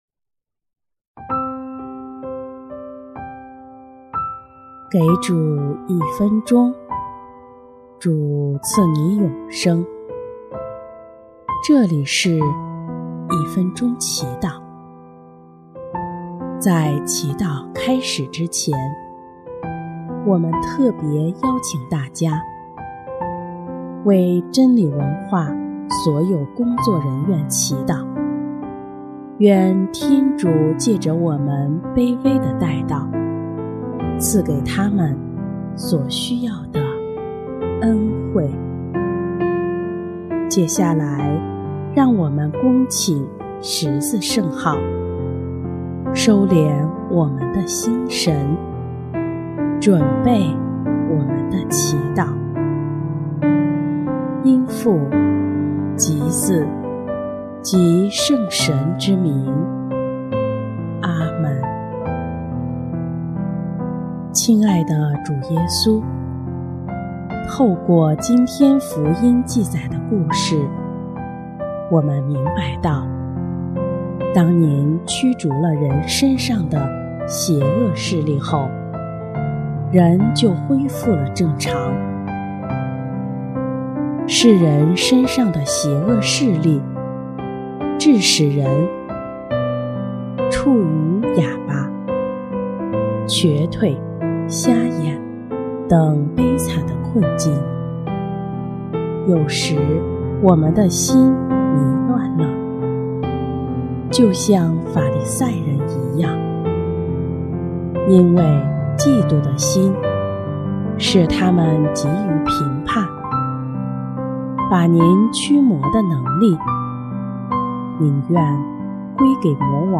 音乐： 第一届华语圣歌大赛参赛歌曲《力量》（为真理文化所有的工作人员祈祷）